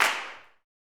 CLAPSUTC8.wav